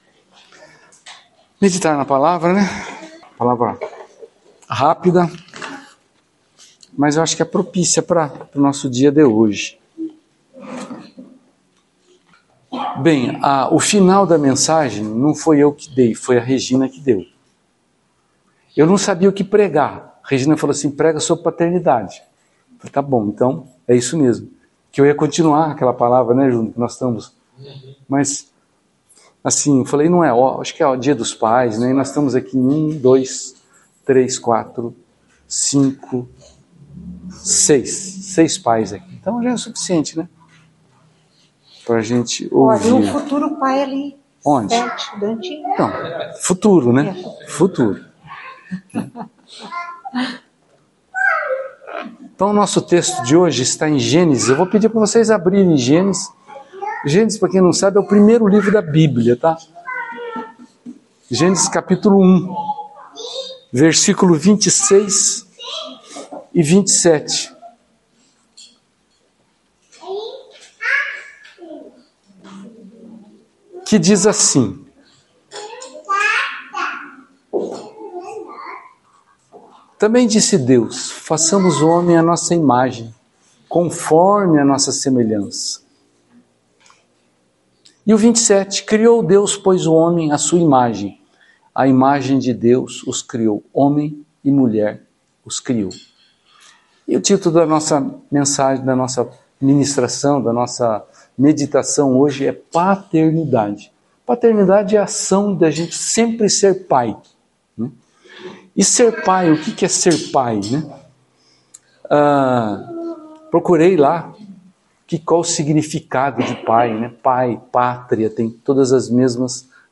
no culto do dia 11/08/2024 – Tema: Paternidade